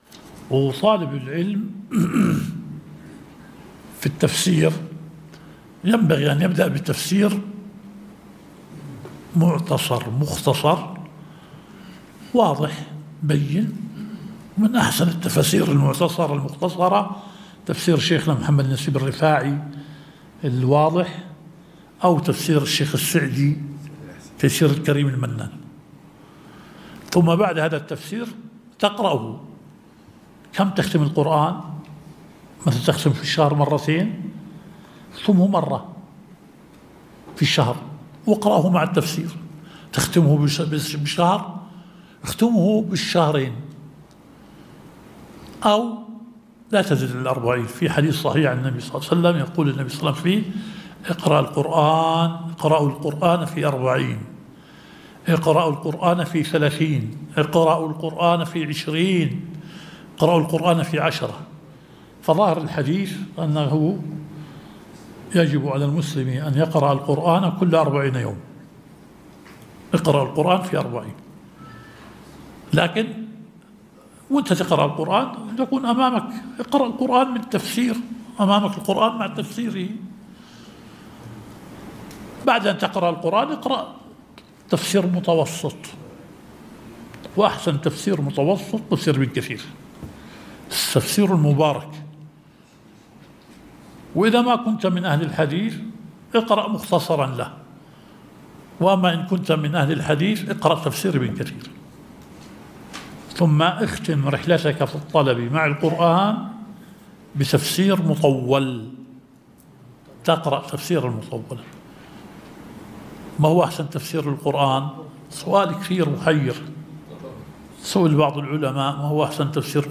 الدرس التاسع – شرح مبحث العام والخاص في أصول الفقه